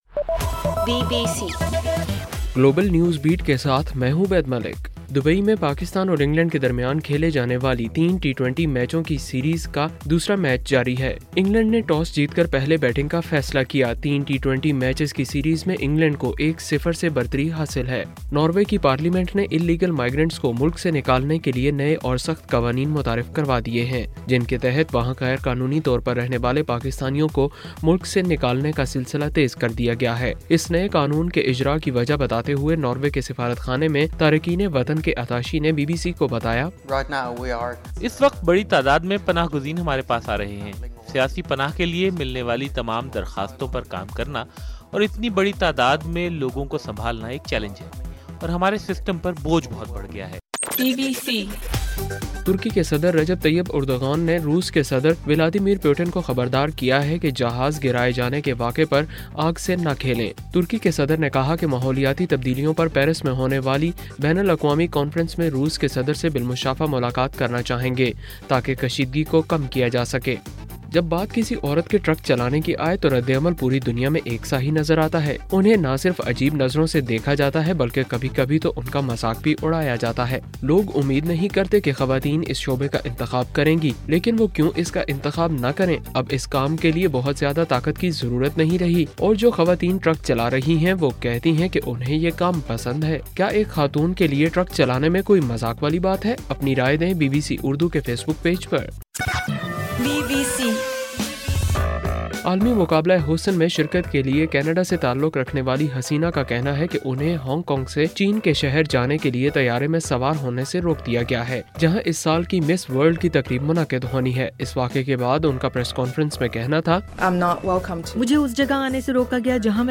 نومبر 27: رات 11 بجے کا گلوبل نیوز بیٹ بُلیٹن